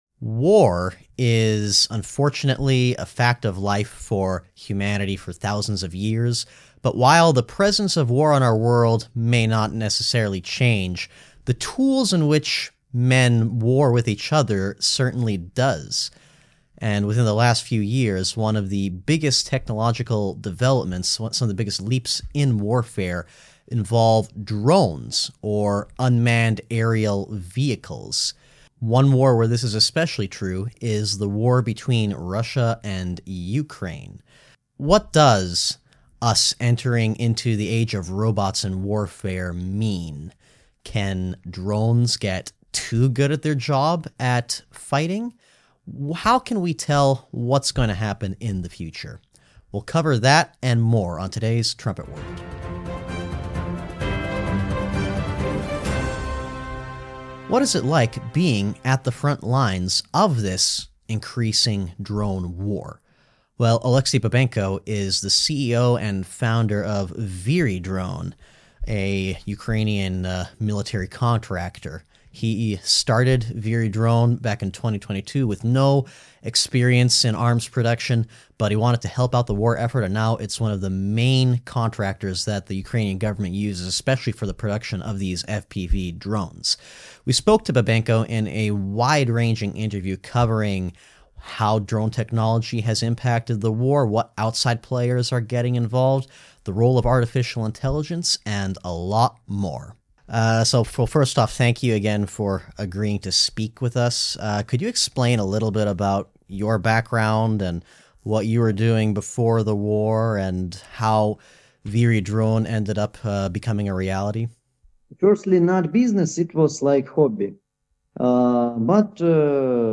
trumpet-world-48-interview-front-lines-of-ukrainian-drones.mp3